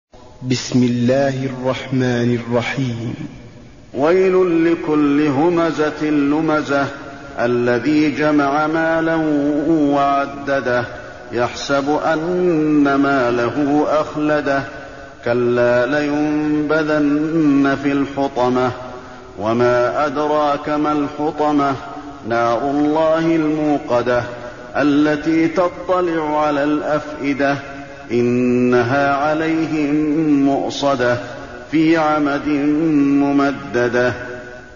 المكان: المسجد النبوي الهمزة